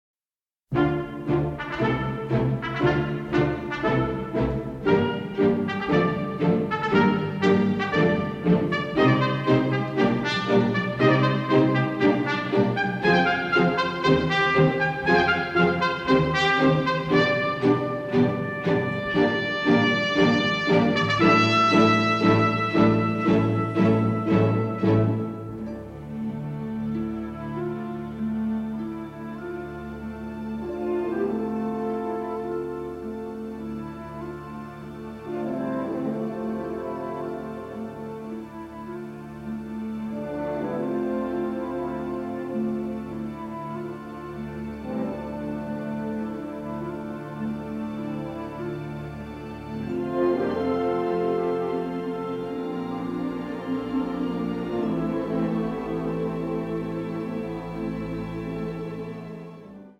THE FILM SCORE (MONO)